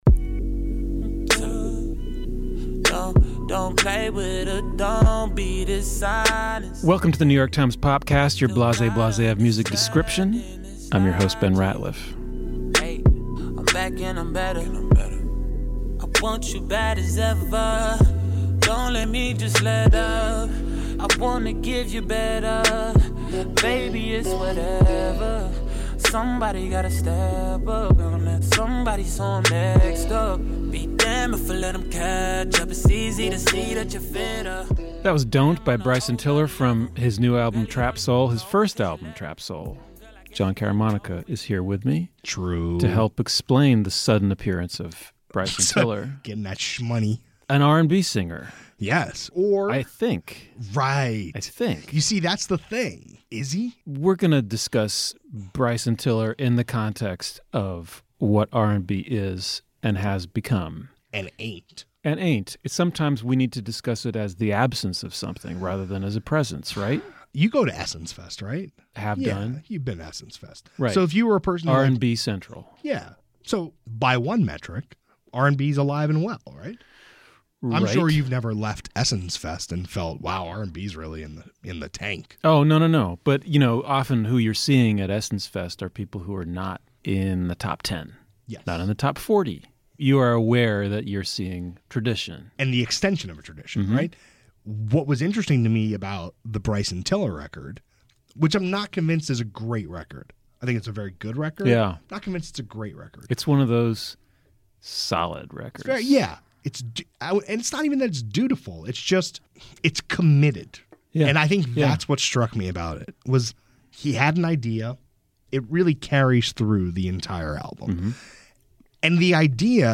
New York Times music critics discuss changes within the genre.